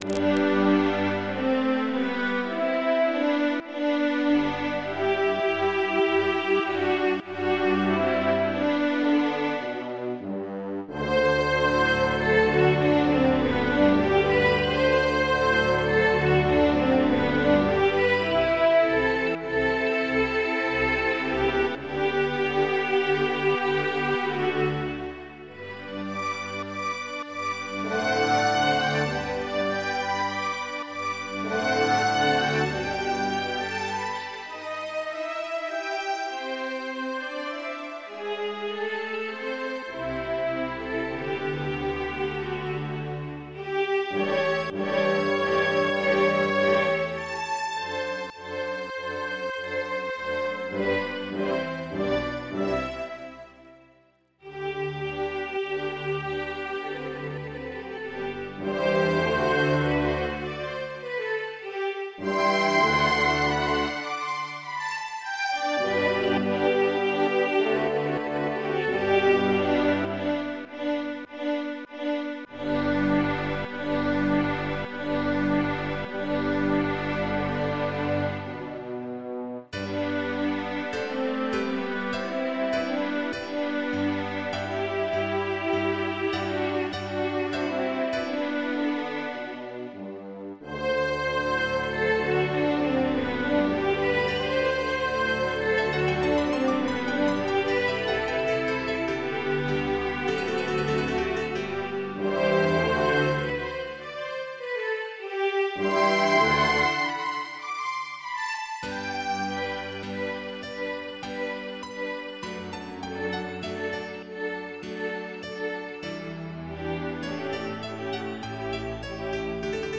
MIDI 45.5 KB MP3 (Converted) 21.69 MB MIDI-XML Sheet Music